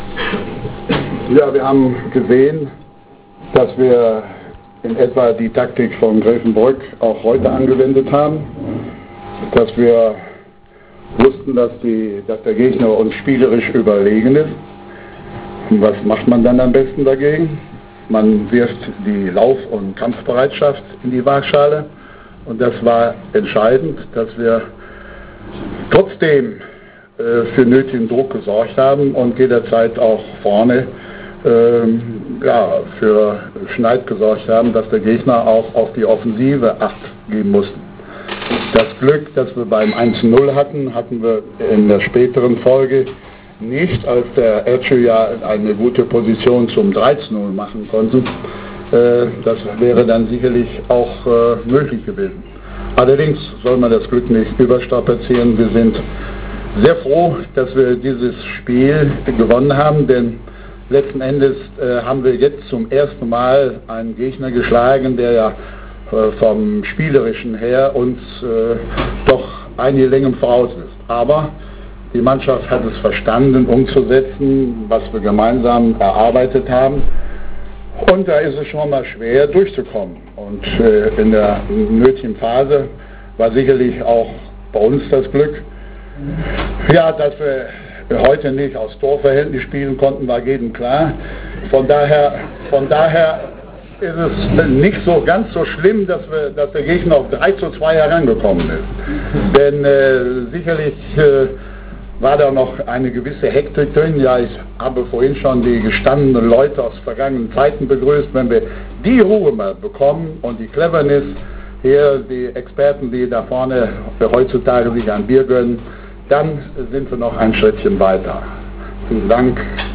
Pressekonferenz (*. wav, abspielbar z.B mit dem Windows Mediaplayer)